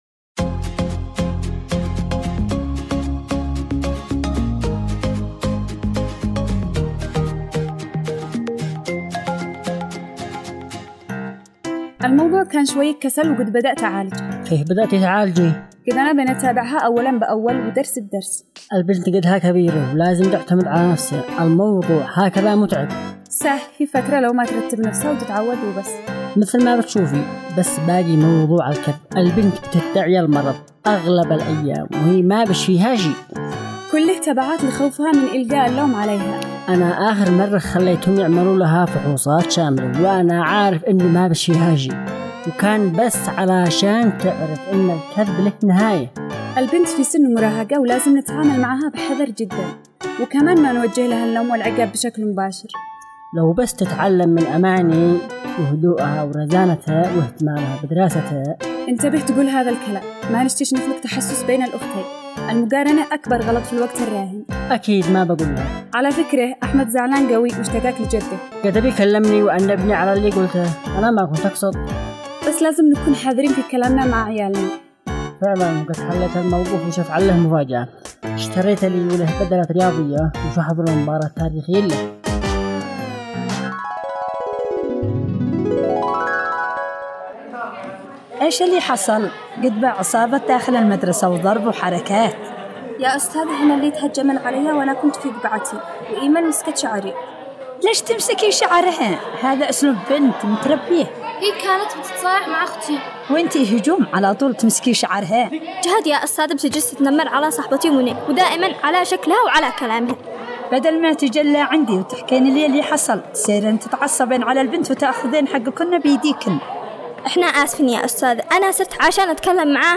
دراما رمضانية - عائلة مصطفى